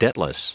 Prononciation du mot debtless en anglais (fichier audio)
Prononciation du mot : debtless